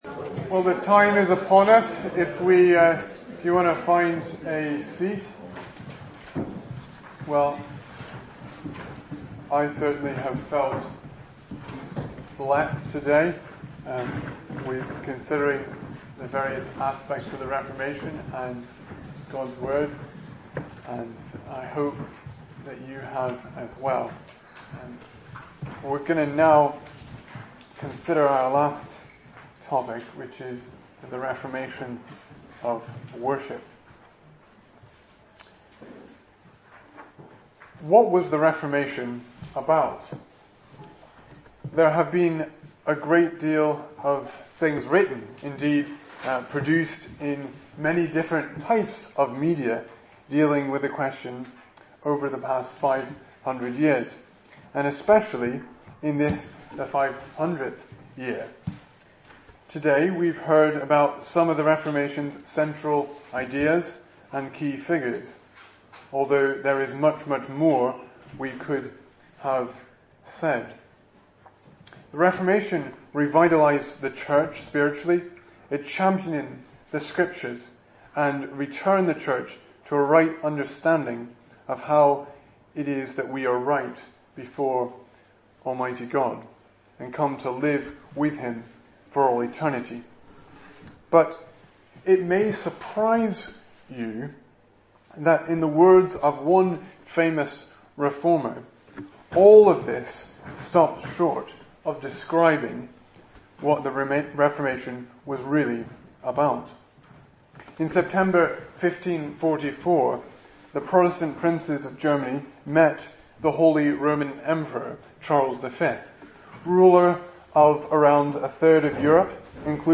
2017 Service Type: Lecture Speaker